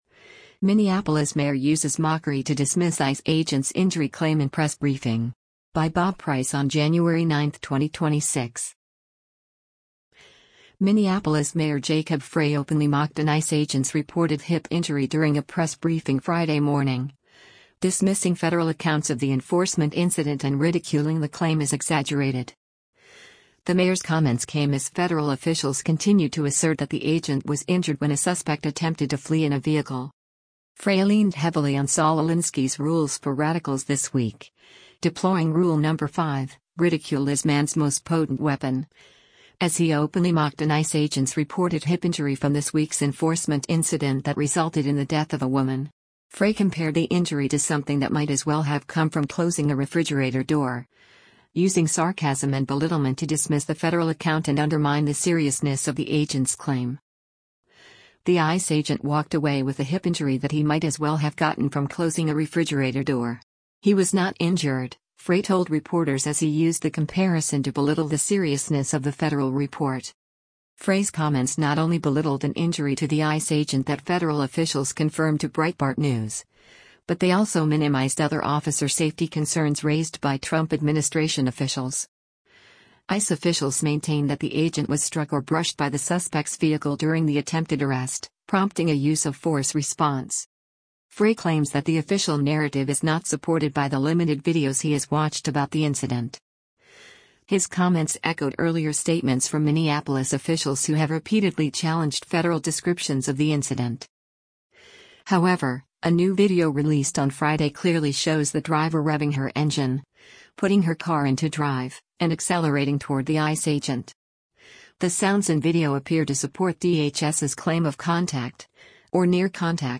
Minneapolis Mayor Jacob Frey openly mocked an ICE agent’s reported hip injury during a press briefing Friday morning, dismissing federal accounts of the enforcement incident and ridiculing the claim as exaggerated.
The mayor’s tone — mocking, dismissive, and pointed — stood out even amid the ongoing dispute between city leadership and federal immigration authorities.